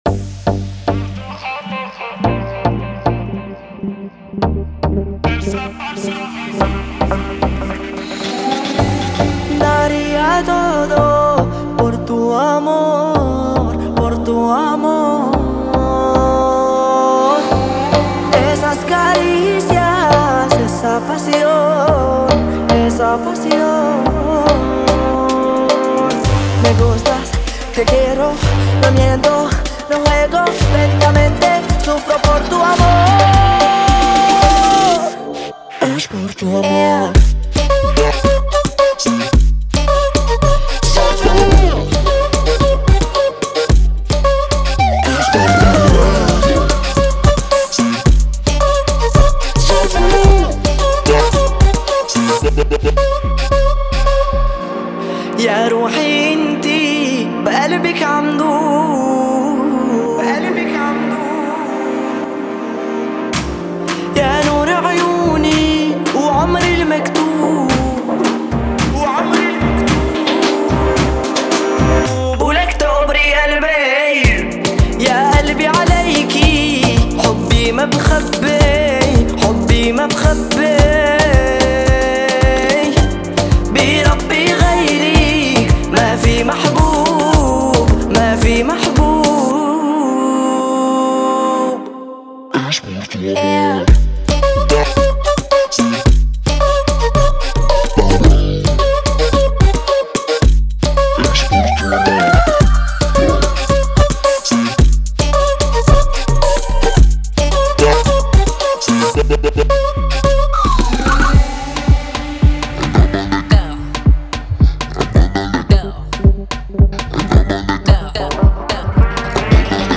это романтическая баллада в жанре латинской музыки